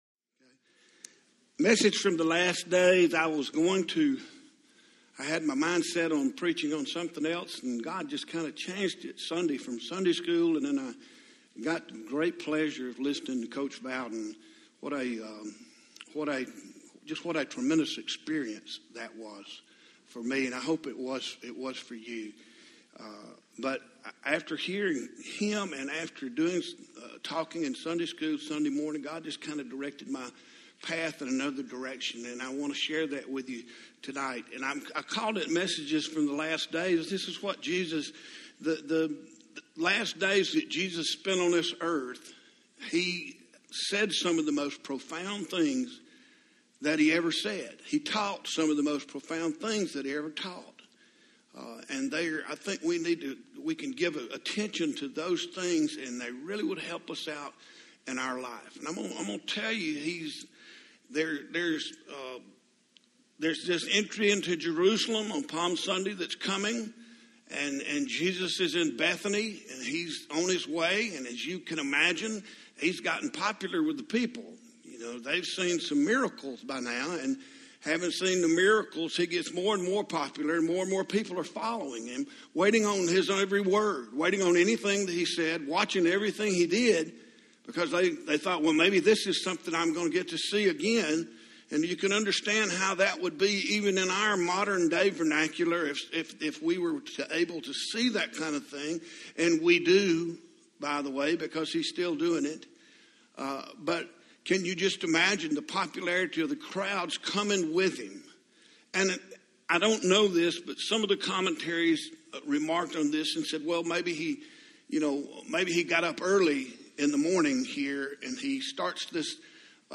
Passion in the Christian Life Audio Sermon